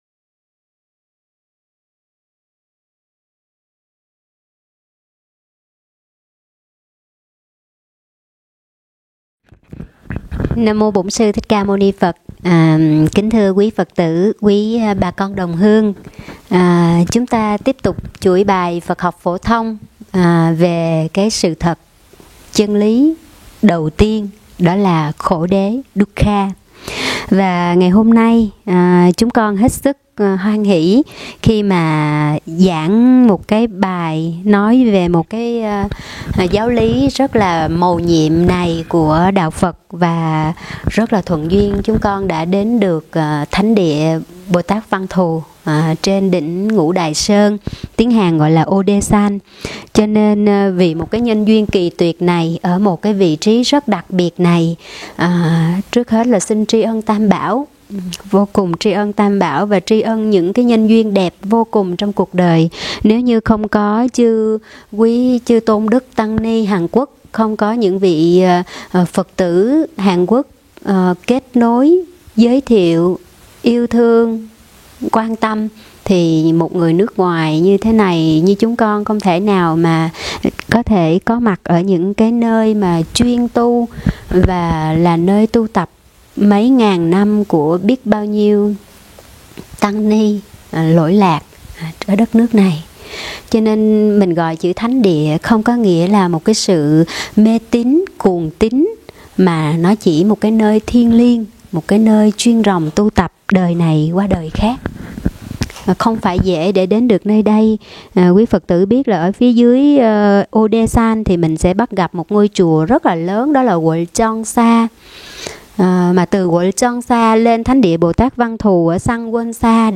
Nghe Mp3 thuyết pháp Khổ đế - Sự thật của cuộc đời